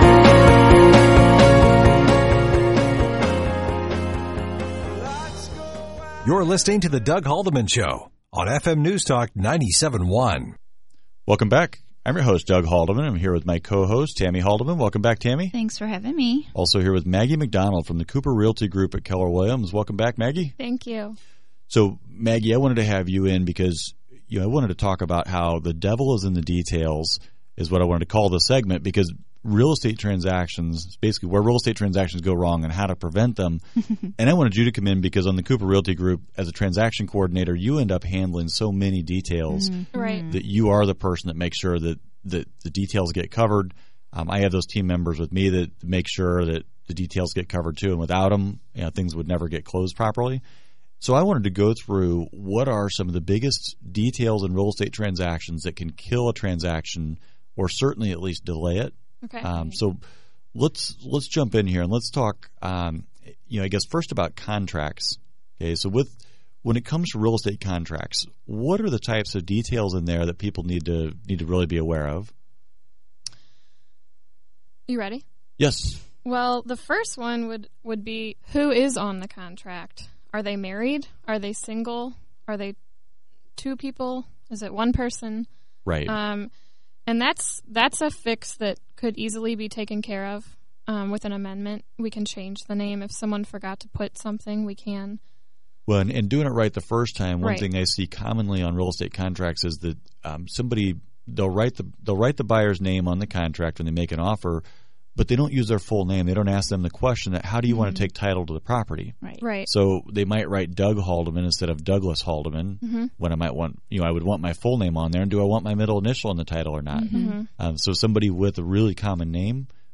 In our interview we break down into the three professions that cover the details and have to deal with solving problems as they occur. We answer all of the questions below in our in-depth interview: